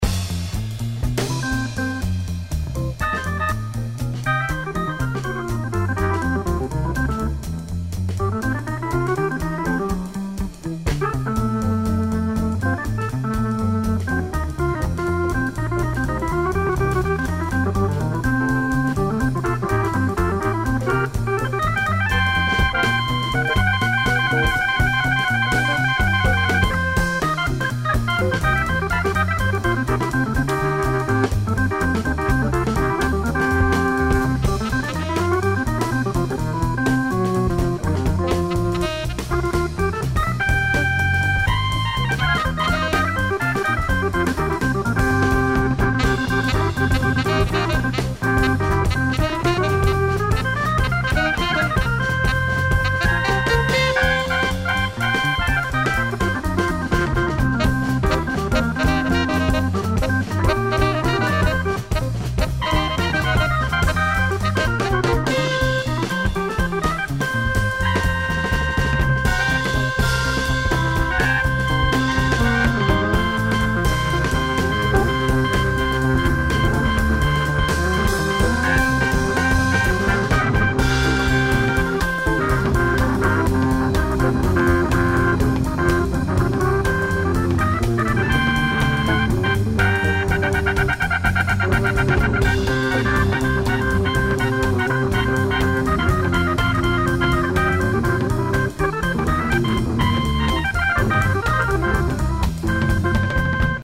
sax